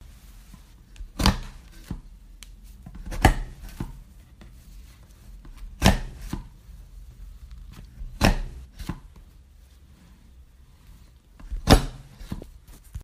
裁纸机
描述：触摸切纸机
Tag: 环境 感知 接触话筒